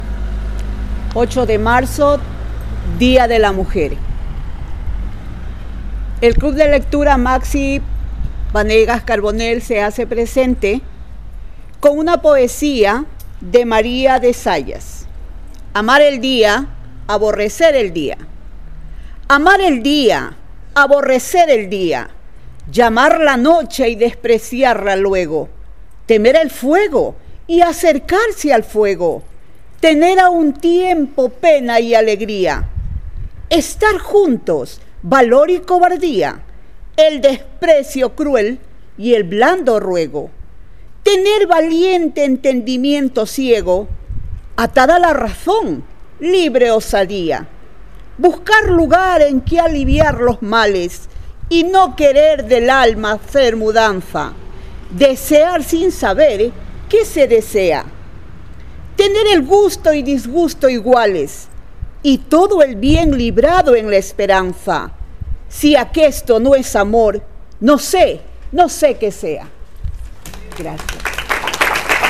Com és habitual a les 20 hores s’ha celebrat l’habitual concentració silenciosa a les portes de l’ajuntament on es trobaven presents en representació de l’equip de govern l’edil d’Igualtat
la lectura d’un poema